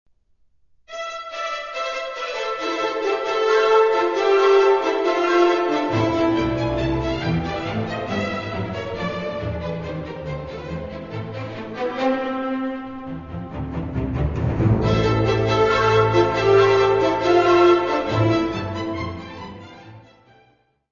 Área:  Música Clássica
Allegro molto - Meno mosso - Tempo I.